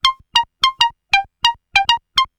/audio/sounds/Extra Packs/musicradar-synth-samples/ARP Odyssey/Arp B Lines/
Arp B Line 03.wav